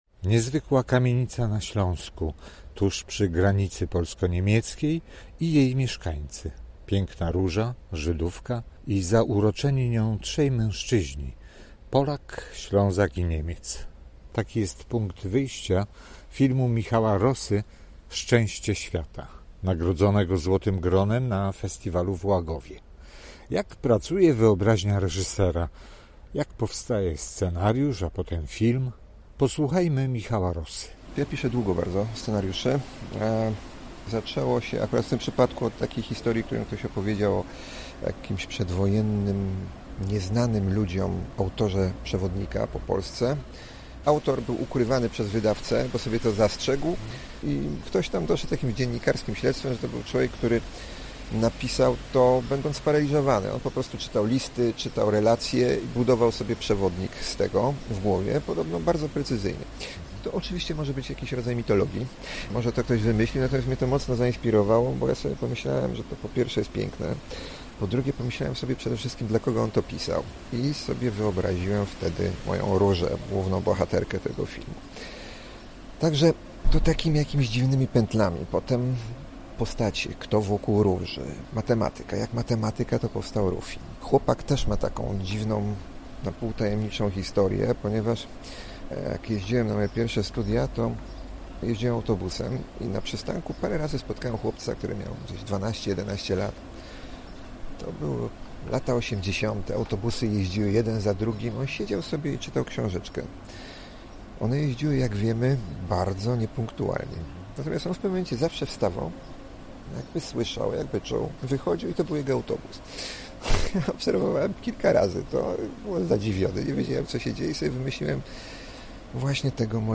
Miałem w Łagowie okazję rozmawiać z najwybitniejszymi twórcami polskiego kina.